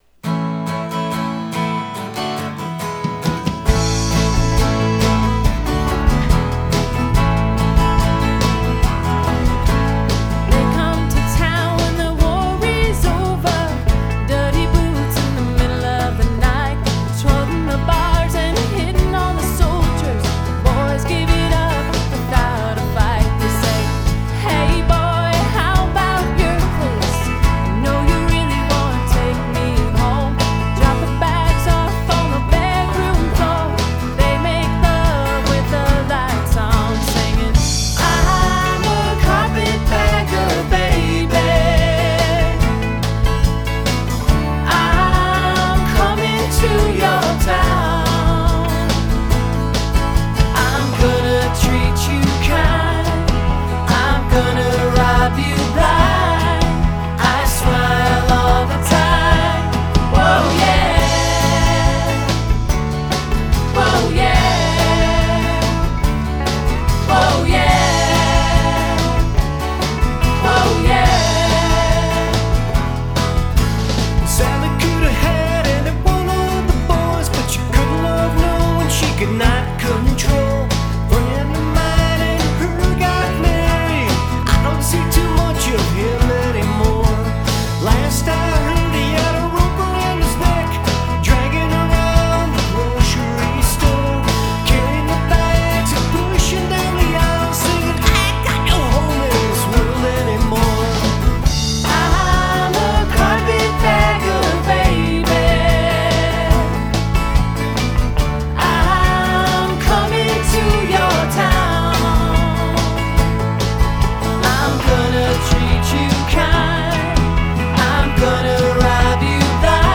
Smooth Rock & Natural Roll
electric guitar and vocals
accoustic guitar and vocals
bass and vocals
banjo
percussion
guitar and vocals